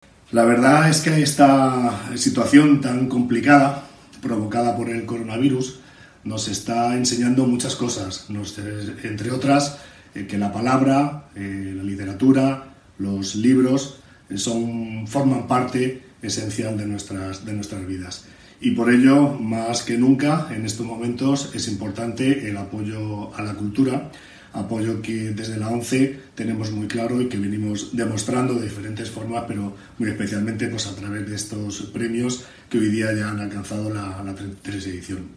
Todos los premiados  agradecen el galardón en una gala difundida en Youtube con intervención de todos los jurados y responsables institucionales